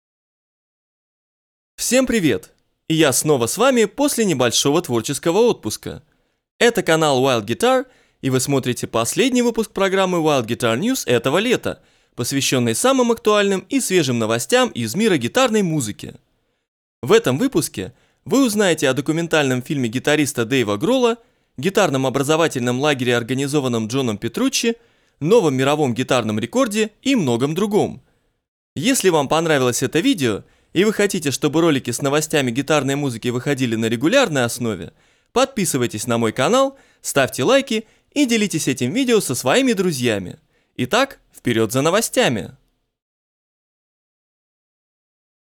Пример дубляжа на русском языке